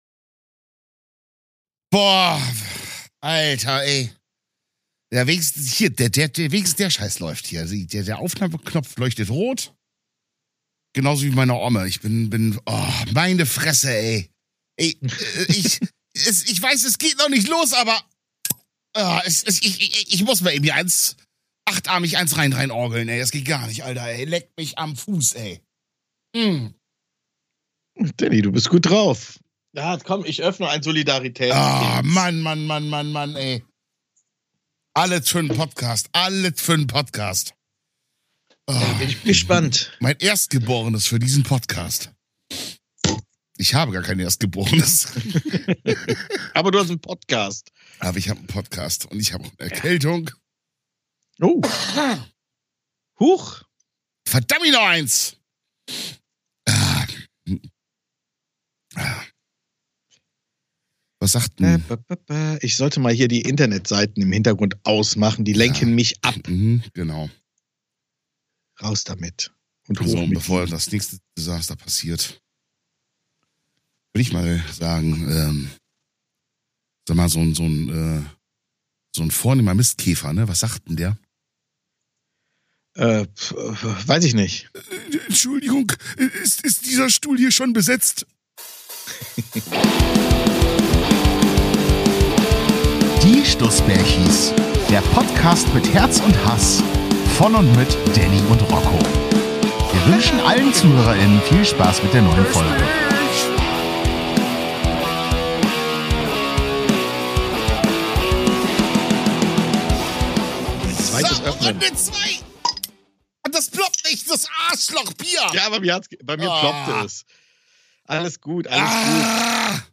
Wir werden Euch in dieser Folge was husten, und zwar kräftig!